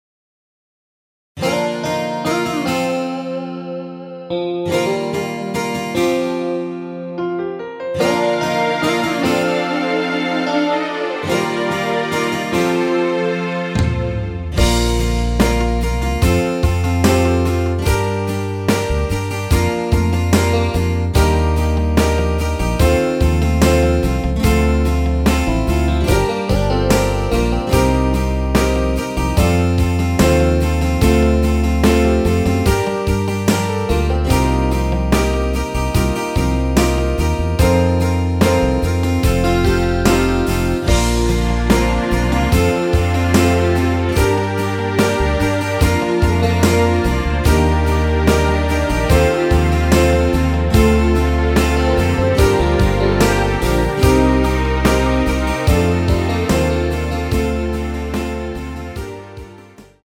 원키에서(-9)내린 MR입니다.
Bb
앞부분30초, 뒷부분30초씩 편집해서 올려 드리고 있습니다.
중간에 음이 끈어지고 다시 나오는 이유는